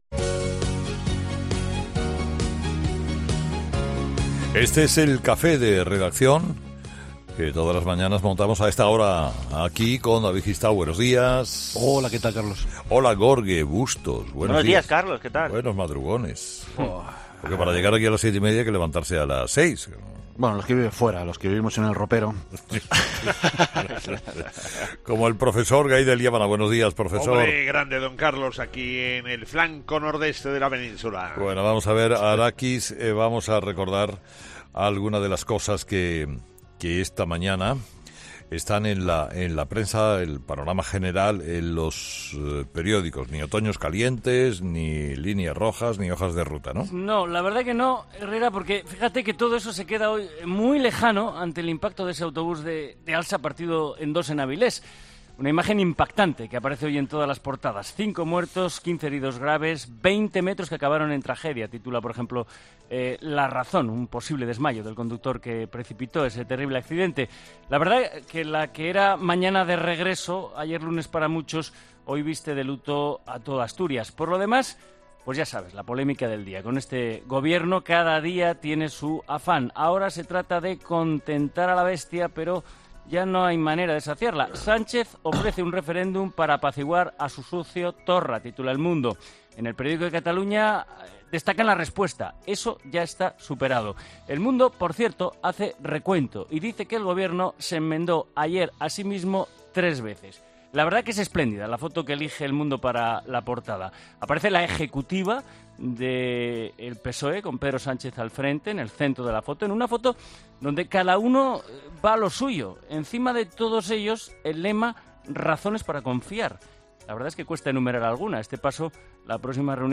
REVISTA DE PRENSA